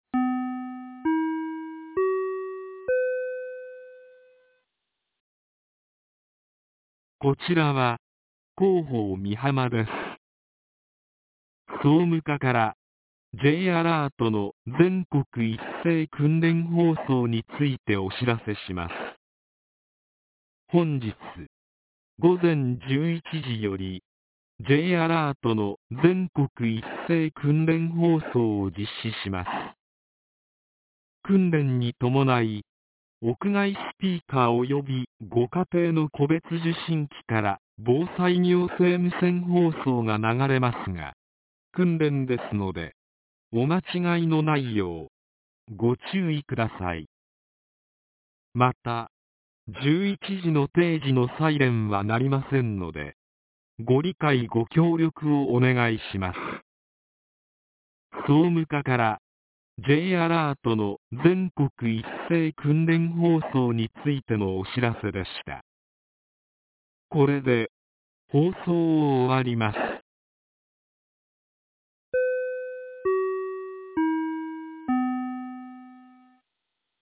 ■防災行政無線情報■ | 三重県御浜町メール配信サービス